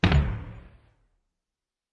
WAR 'n' BATTLE声音 模拟和实验 " 01448 遥远的大爆炸 2
描述：烟花表演时，变焦H2模拟的远处导弹爆炸
Tag: 热潮 遥远 爆炸 爆炸 手榴弹 导弹